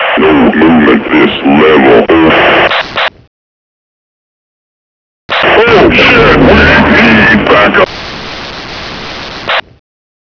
hecu_radio1.wav